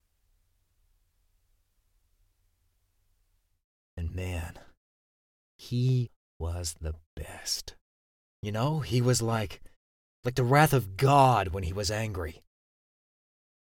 Male
English (Australian)
Adult (30-50)
Start with a warm, reassuring baritone.
Video Game Work Examples
Studio Quality Sample